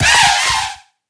Cri de Charibari dans Pokémon HOME.